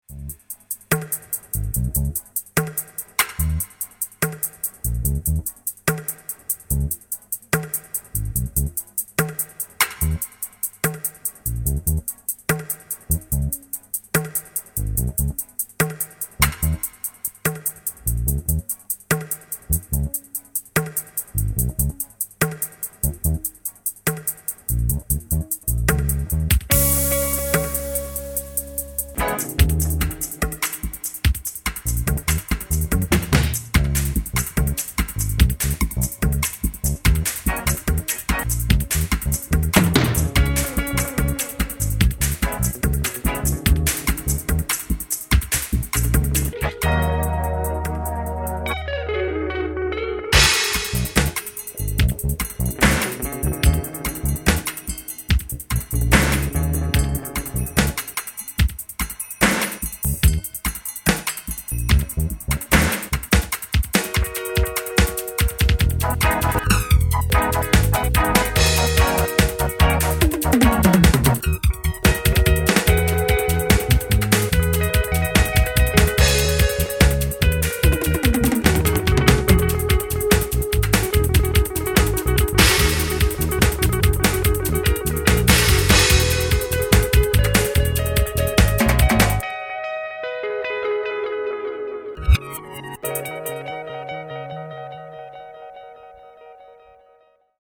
REGGAE DUB SECTION